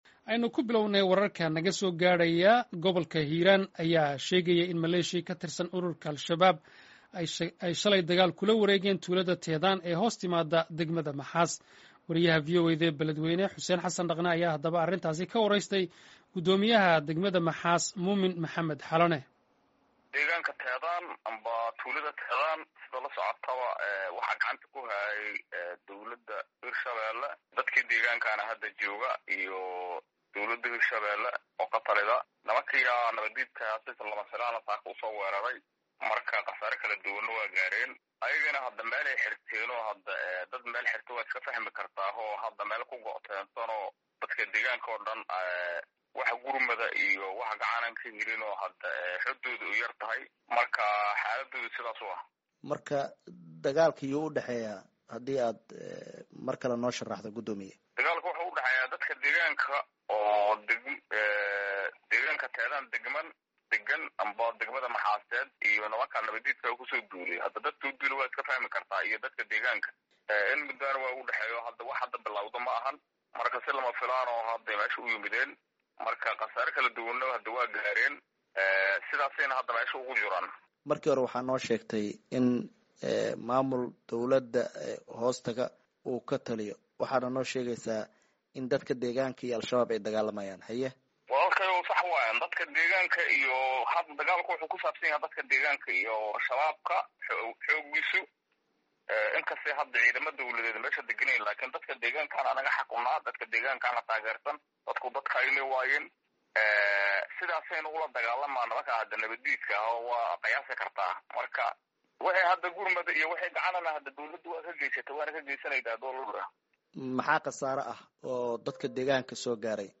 ayaa arrintan ka wareystay,guddoomiyaha degmada Maxaas Muumin Maxamed Xalane.